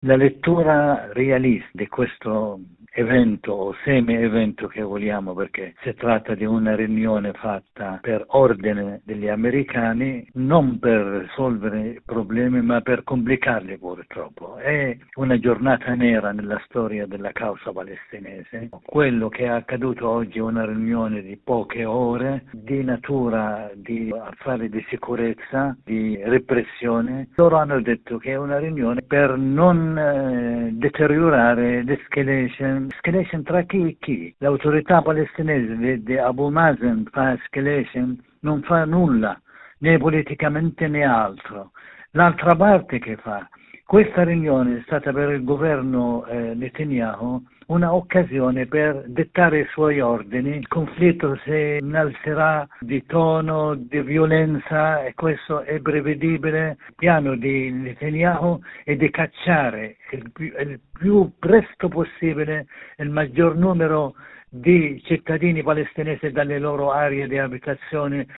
Il commento del giornalista palestinese